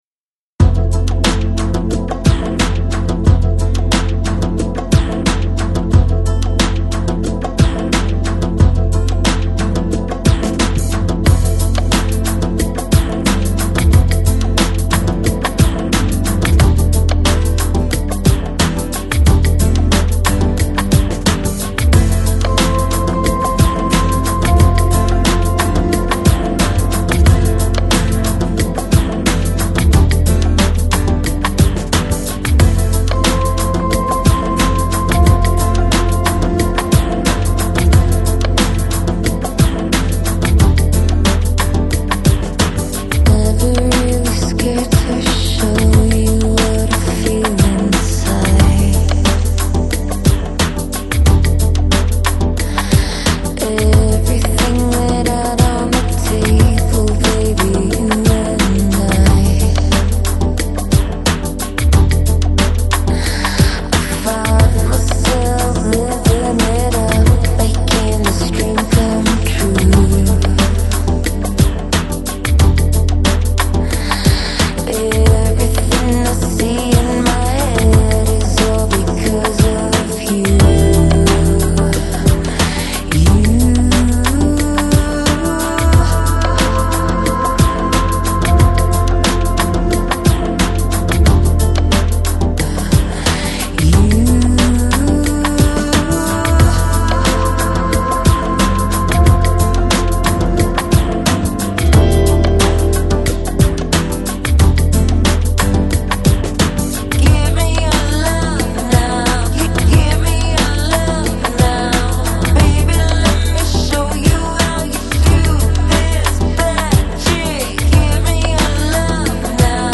Electronic, Downtempo, Balearic, Chill House